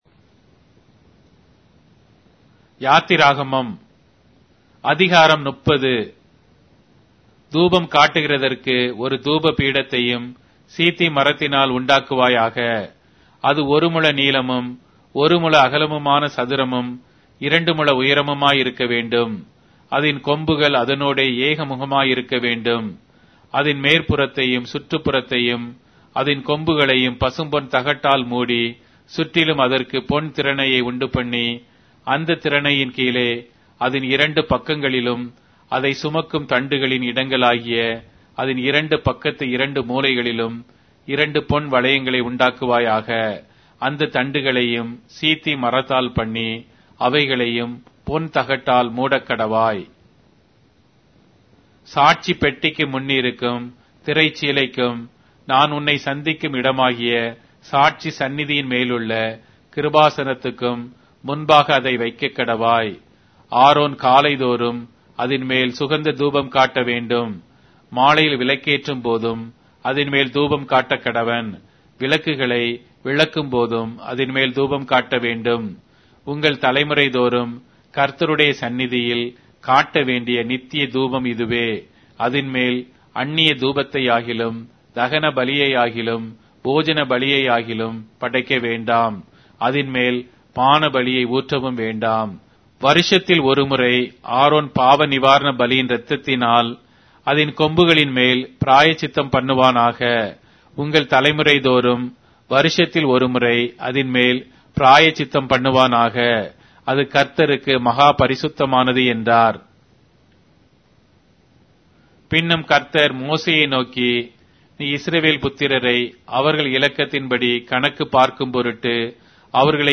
Tamil Audio Bible - Exodus 1 in Mrv bible version